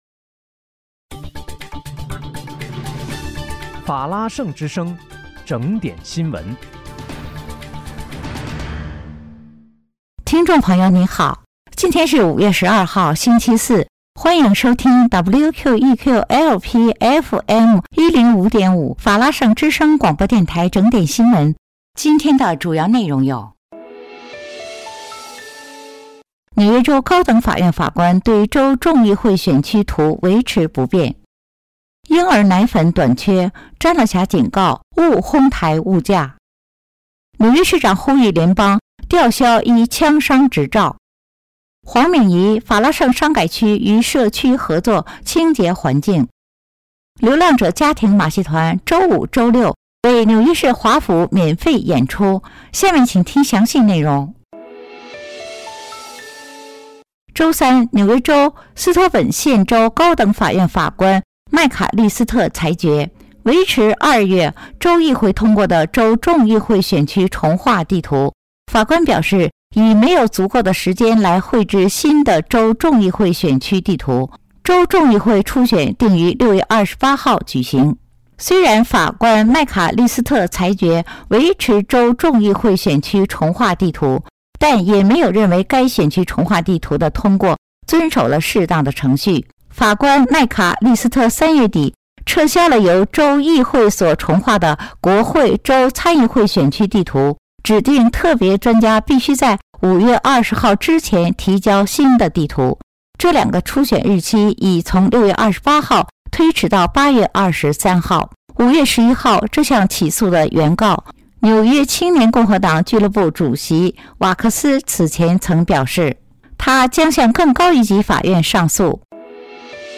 5月12日（星期四）纽约整点新闻